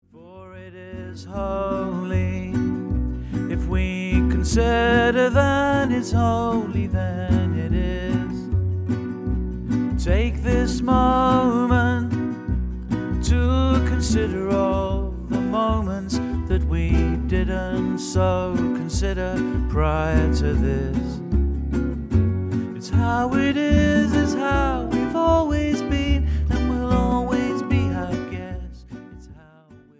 collection of family stories in song